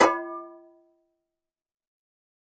fo_fryingpan_clang_03_hpx
Frying pans clang together. Clang, Frying Pan